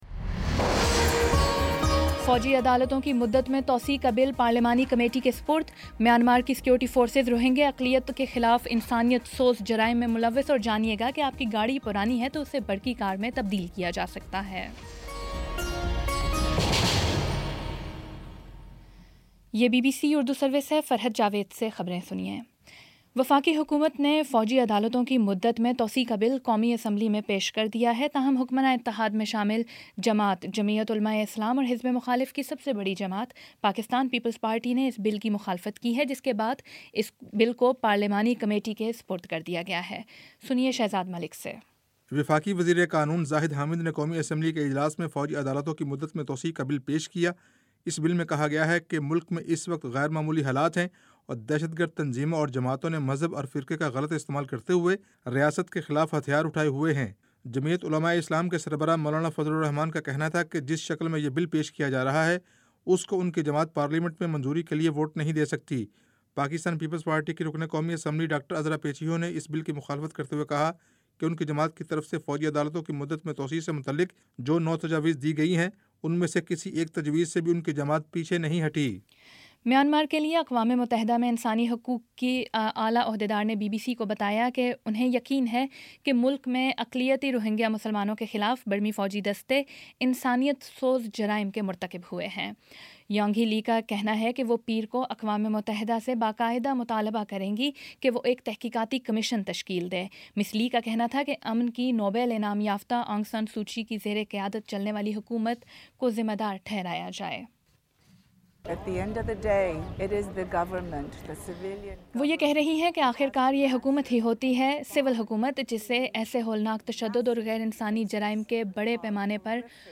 مارچ 10 : شام پانچ بجے کا نیوز بُلیٹن